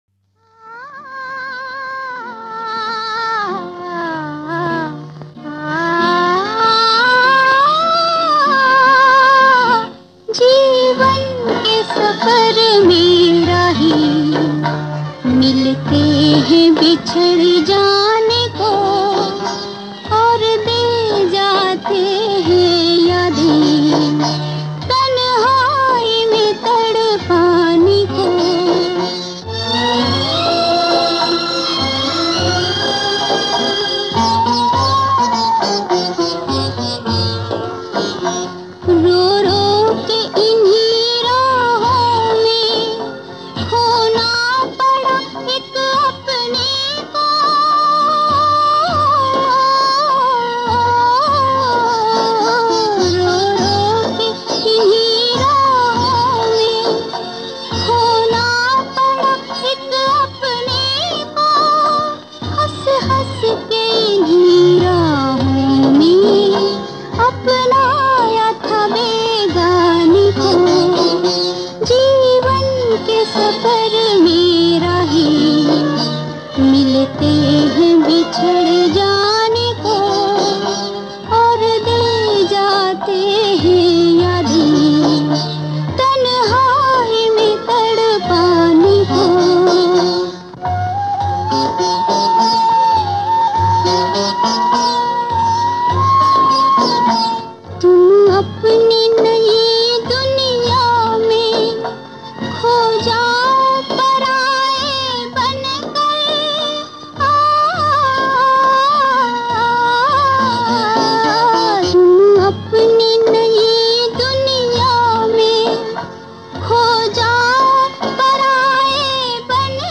Female Vocals